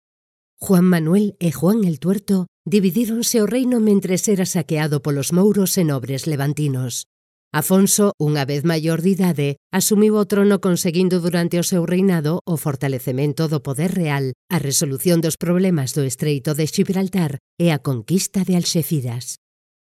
Galician female voice over